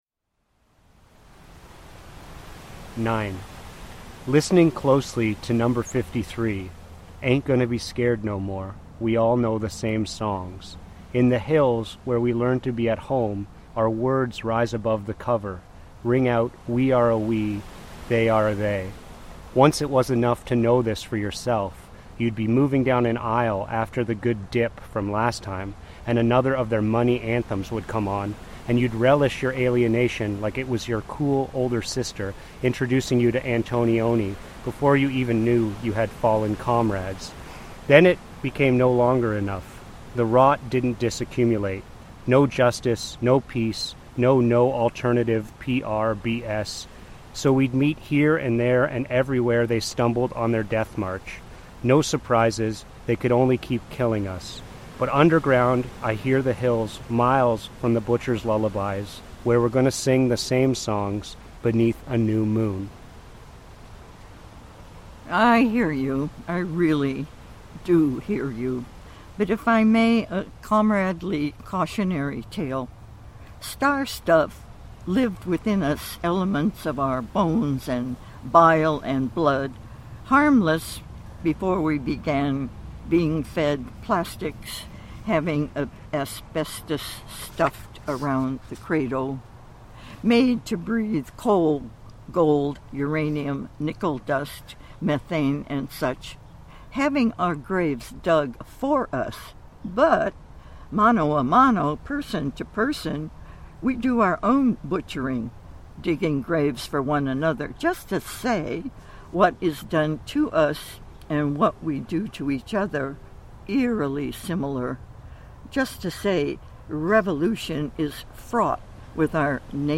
reading a selection from Disharmonies: